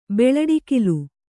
♪ beḷaḍikilu